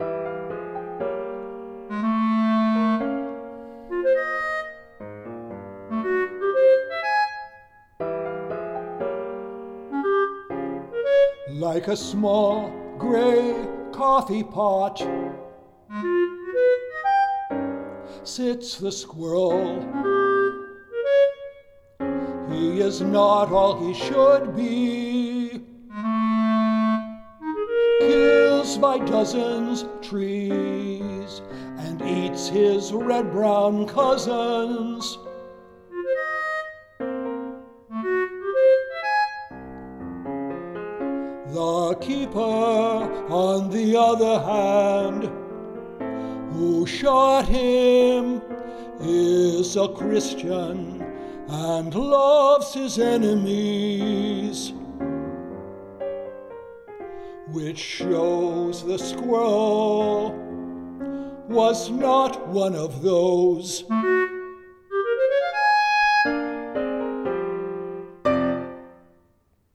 Solo voice, Bb Clarinet, and Piano